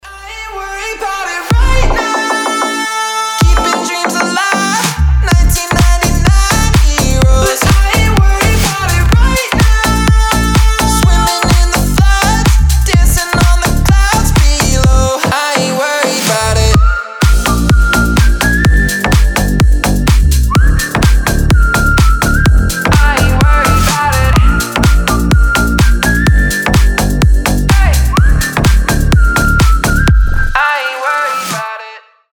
позитивные
свист
басы
ремиксы
slap house